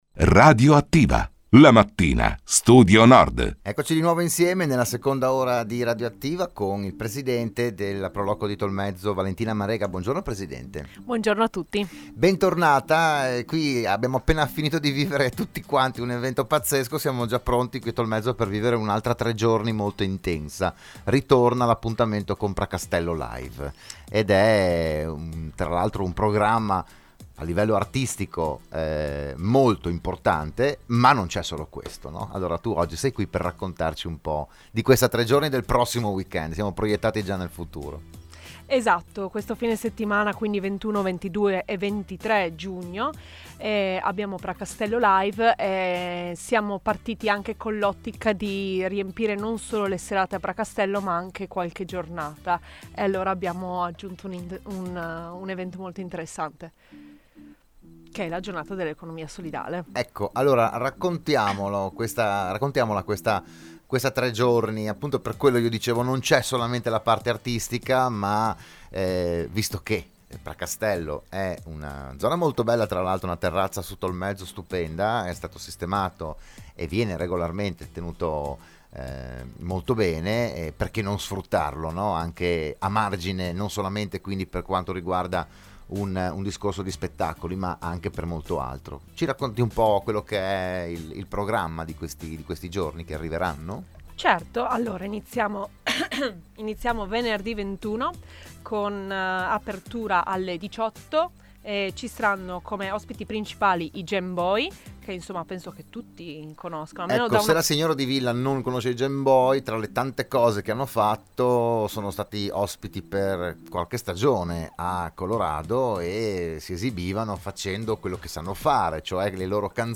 RadioAttiva